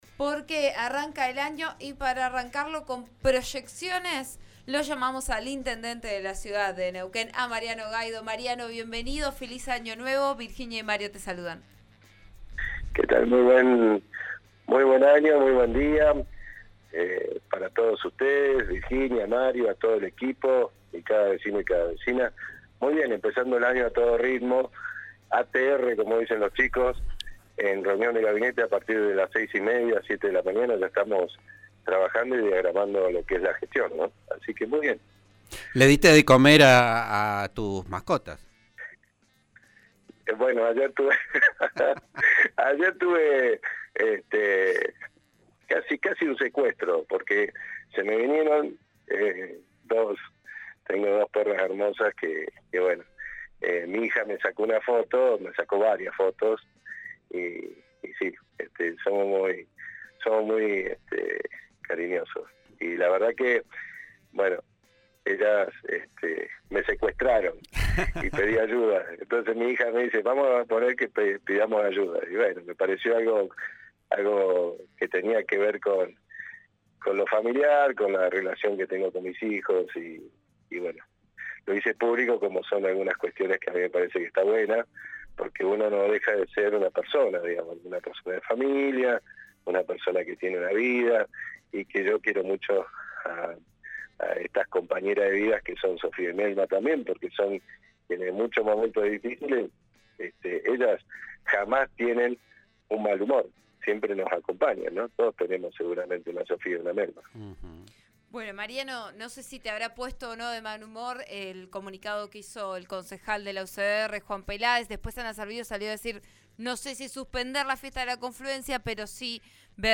En declaraciones con el programa Vos A Diario de RN RADIO, el jefe comunal aseguró que en 45 días hará el llamado a licitación del tramo del Paseo Costero que va desde la calle Bejarano hasta Balsa Las Perlas para sumar este nuevo espacio a las zonas recreativas de la ciudad.